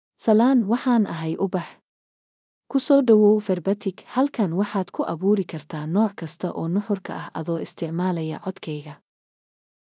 Ubax — Female Somali (Somalia) AI Voice | TTS, Voice Cloning & Video | Verbatik AI
Ubax is a female AI voice for Somali (Somalia).
Voice sample
Listen to Ubax's female Somali voice.
Female
Ubax delivers clear pronunciation with authentic Somalia Somali intonation, making your content sound professionally produced.